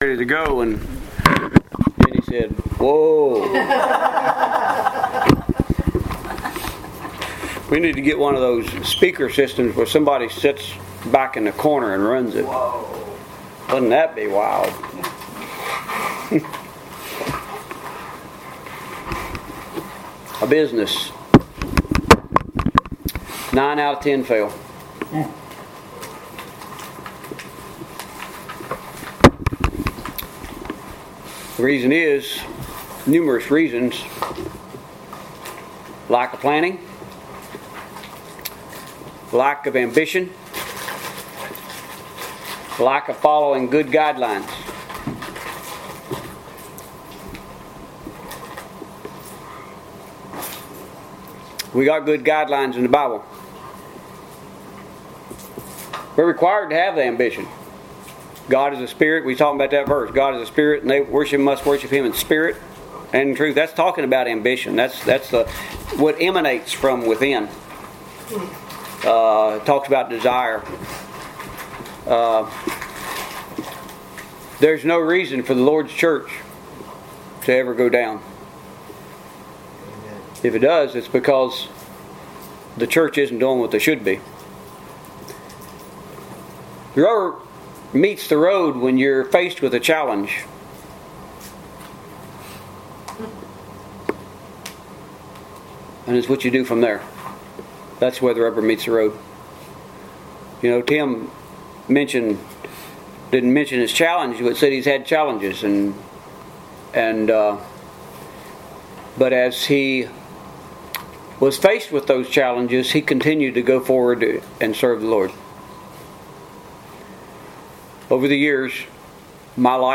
The Church is Like a Business, Part 2 Bible , business , church , scripture , sermon Post a comment Cancel Reply You must be logged in to post a comment.